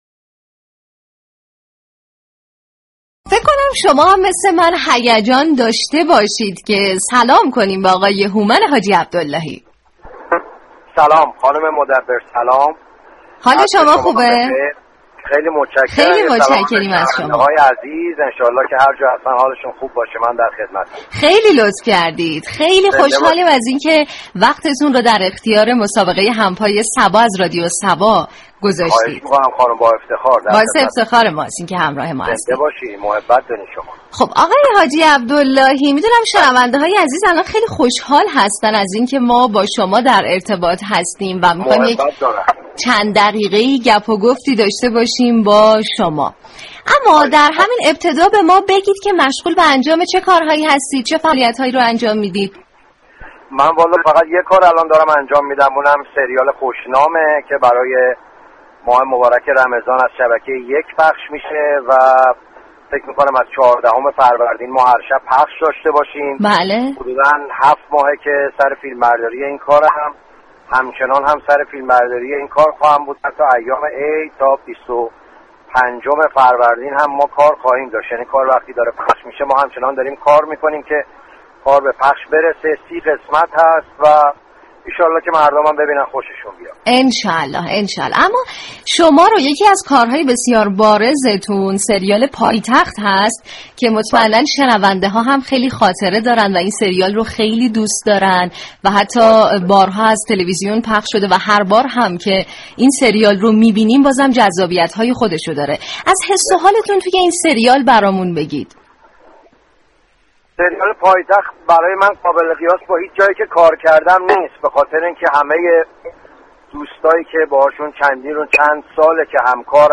هومن حاجی عبداللهی در گفتگو با رادیو صبا از بازیگری در نقش متفاوت در مجموعه ویژه ماه رمضان خبر داد